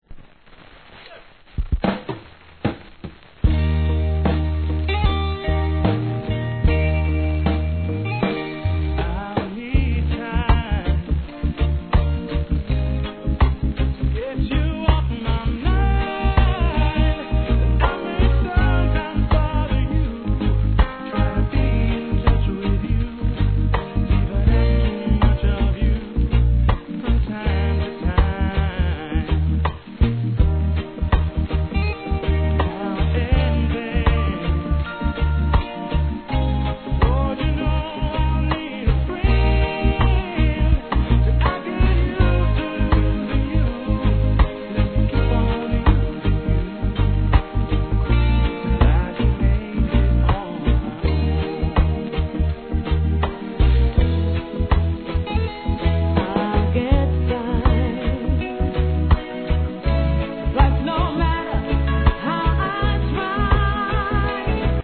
REGGAE
GOODミディアムで聴かせるデュエット物♪